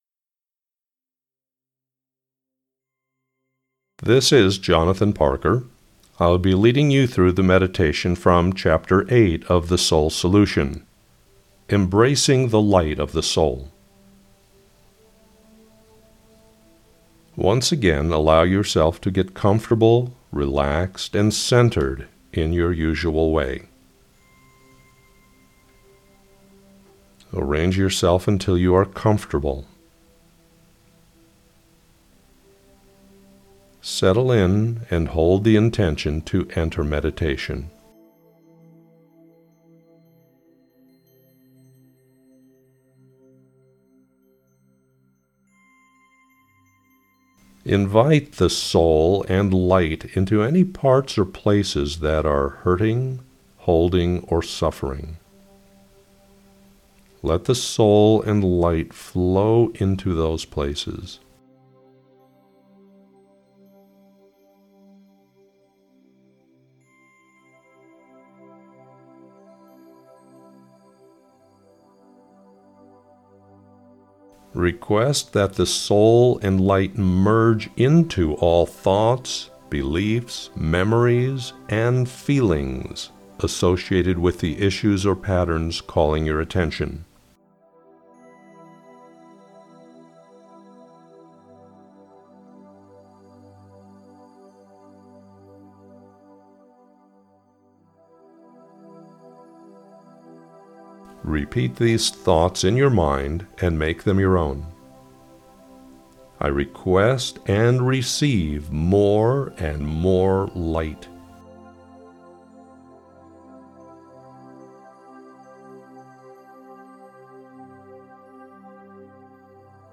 meditation_15_min_the_soul_light.mp3